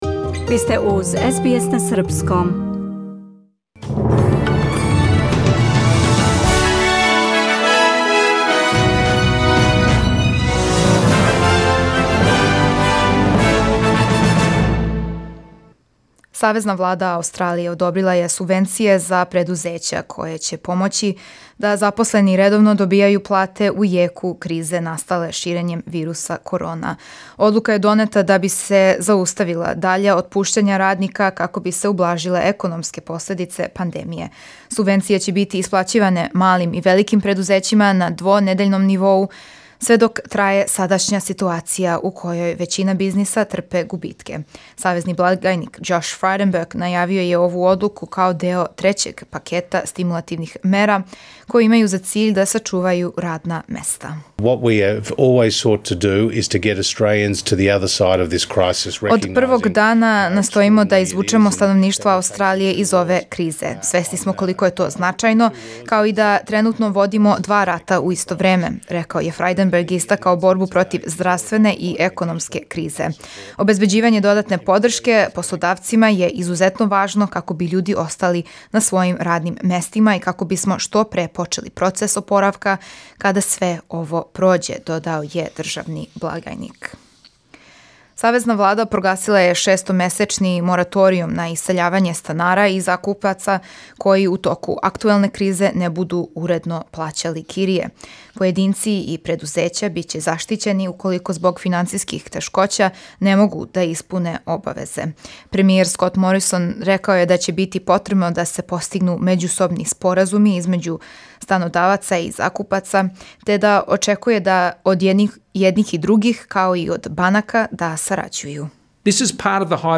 Преглед вести за 30. март 2020. године